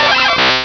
Cri de Kabutops dans Pokémon Rubis et Saphir.